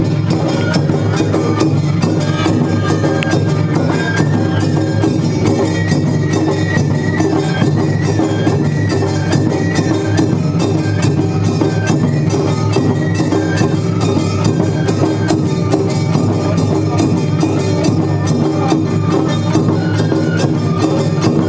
−　阿波踊り　−
街中ではどこからともなく阿波踊りの音楽が